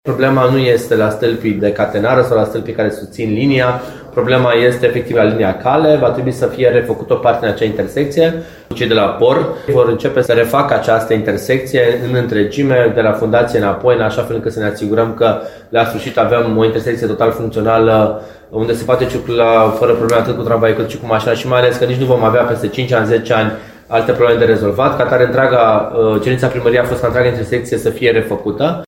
Viceprimarul Ruben Lațcău a precizat că s-a ajuns în această situație din cauza liniei cale, care nu corespunde standardelor în zona Ciocanul, astfel că tramvaiele nu pot întoarce.